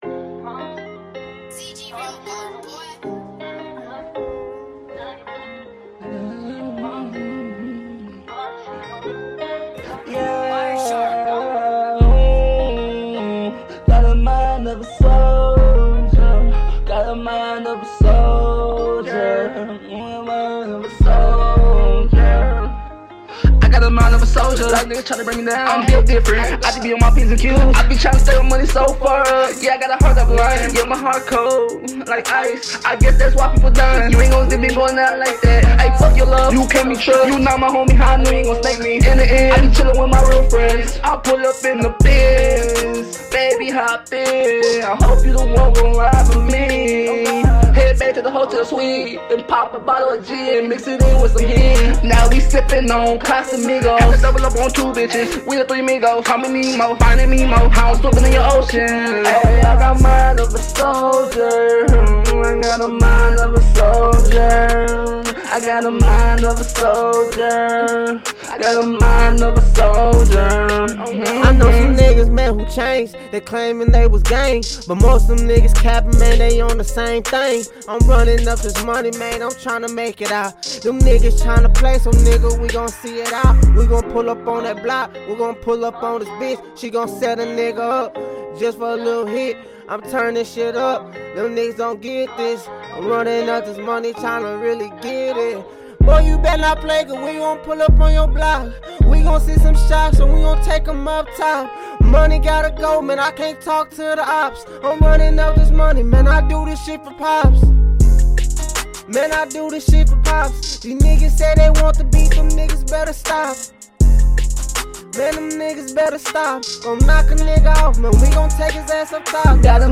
American hip hop